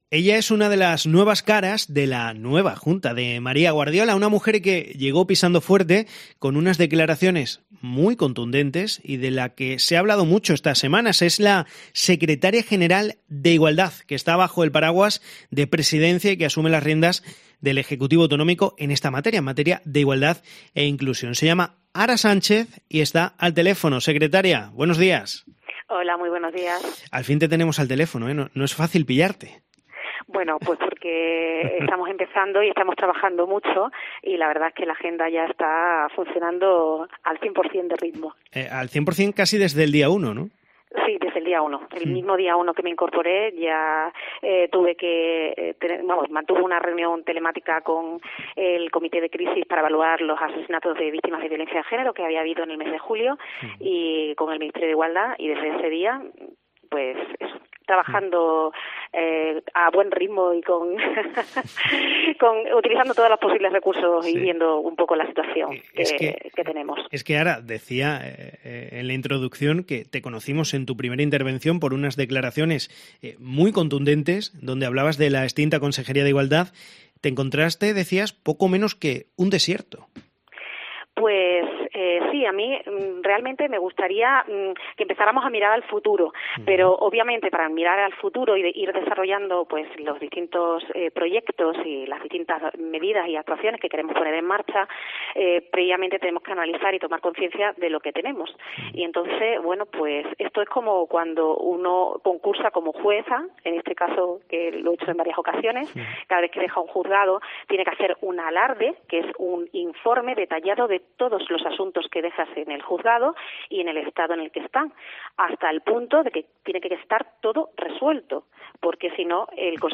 La secretaria de Igualdad y Conciliación de la Junta de Extremadura, la jueza Ara Sánchez , ha concedido una amplia entrevista a Cope Extremadura, donde ha hablado, largo y tendido, de varios asuntos referentes a las competencias que ya asume en el ejecutivo autonómico, bajo el paraguas de Presidencia.